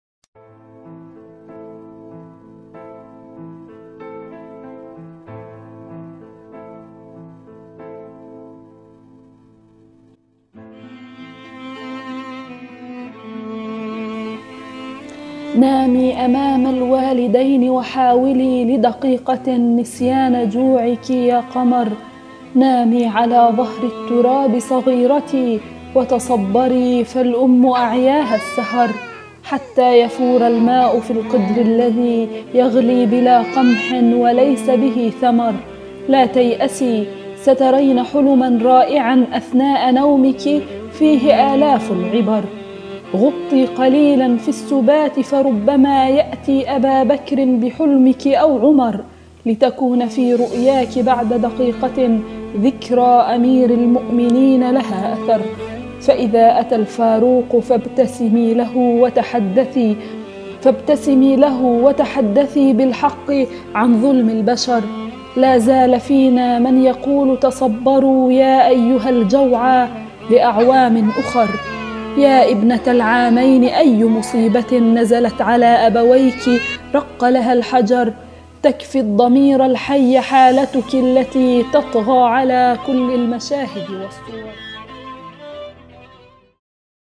قصيدة شعرية بعنوان - محنة أهلنا في اليمن